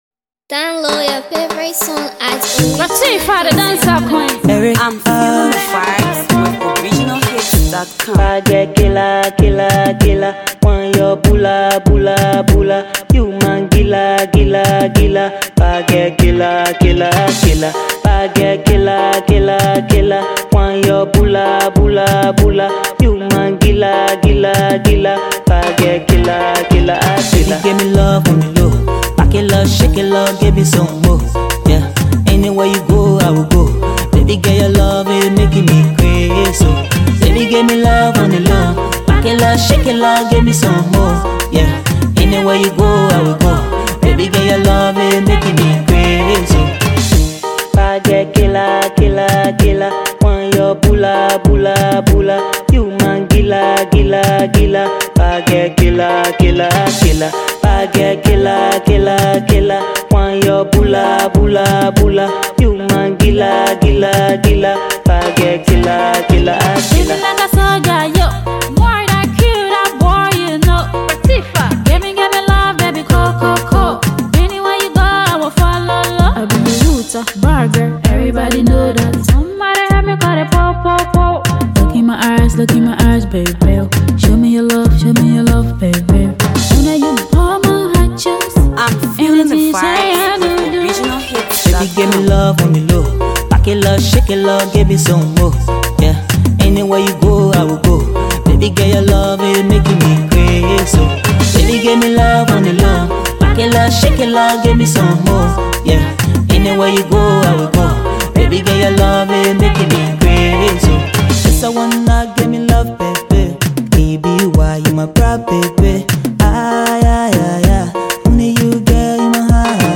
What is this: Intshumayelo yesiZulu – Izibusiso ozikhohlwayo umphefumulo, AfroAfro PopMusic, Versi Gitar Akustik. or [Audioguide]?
AfroAfro PopMusic